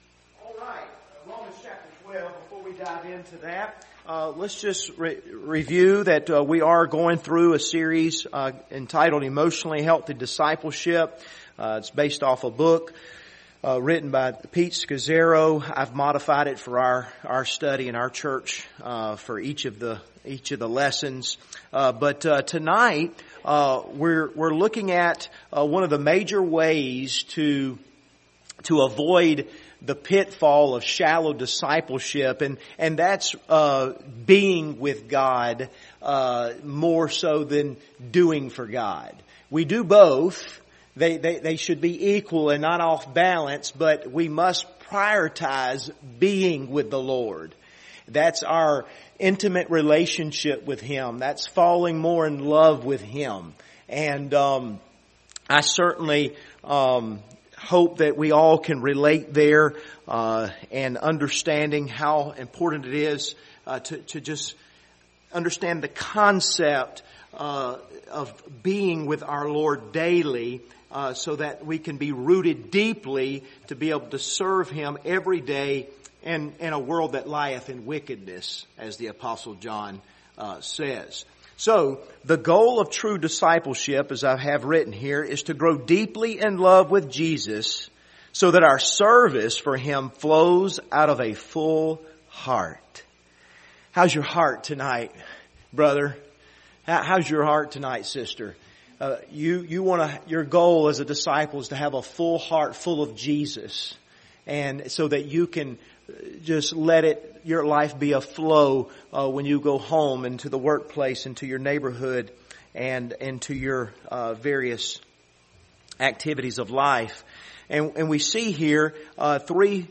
Passage: Romans 12 Service Type: Wednesday Evening